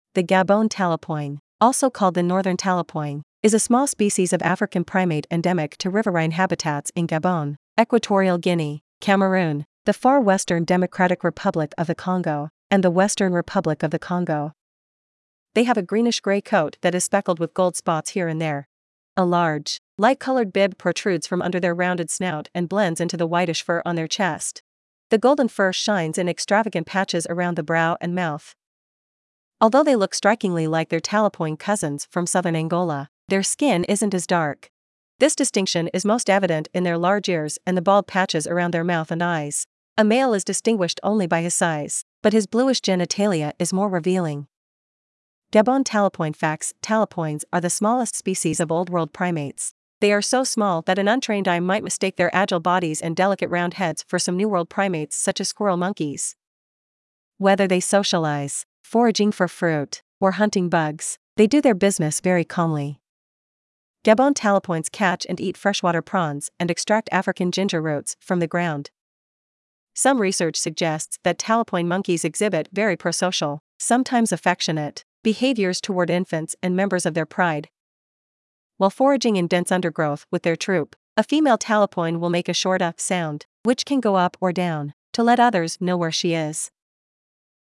• While foraging in dense undergrowth with their troop, a female talapoin will make a short “uh” sound, which can go up or down, to let others know where she is.
Gabon-Talapoin.mp3